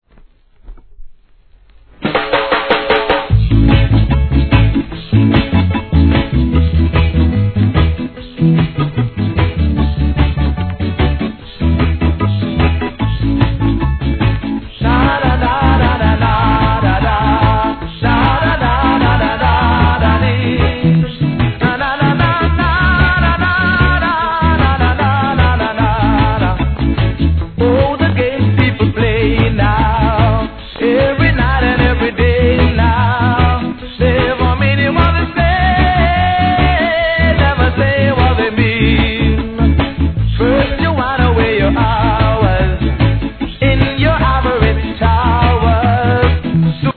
7inch
REGGAE